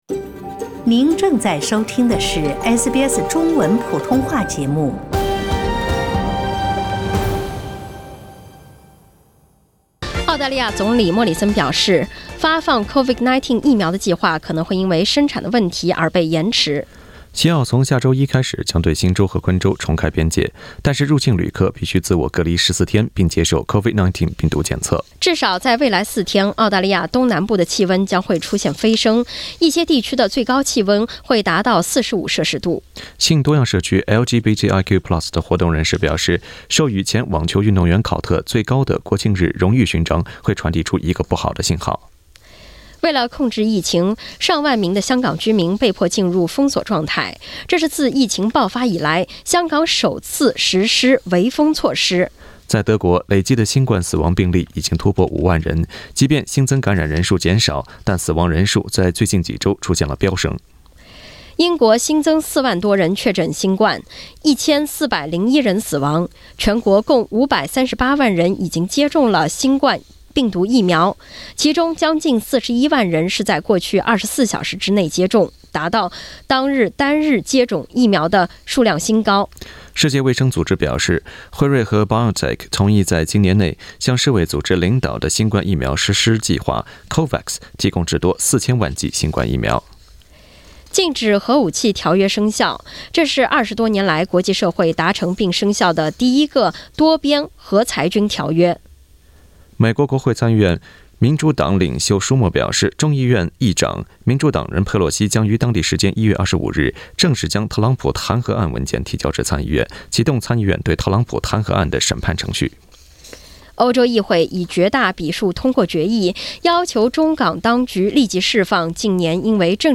SBS早新闻（1月23日）